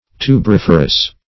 Search Result for " tuberiferous" : The Collaborative International Dictionary of English v.0.48: Tuberiferous \Tu`ber*if"er*ous\, a. [Tuber + -ferous.] Producing or bearing tubers.